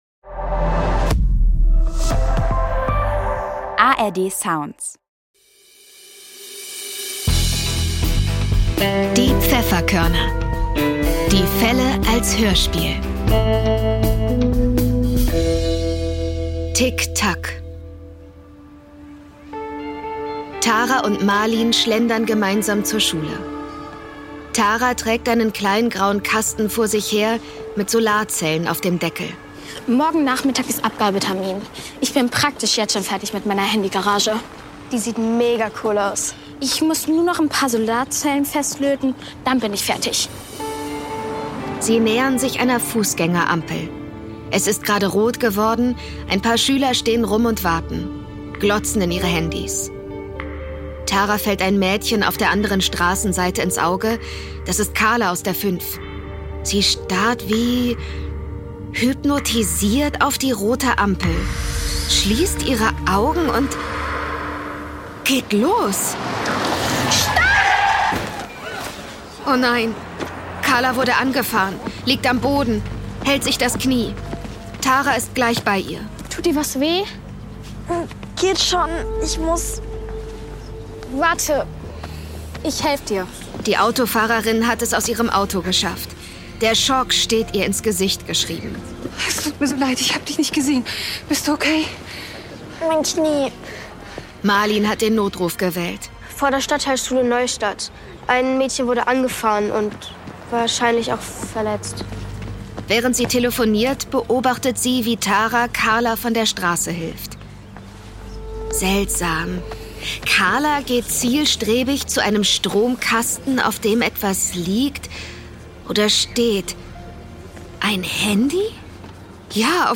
Tick Tack (12/21) ~ Die Pfefferkörner - Die Fälle als Hörspiel Podcast